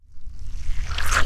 toothpaste.ogg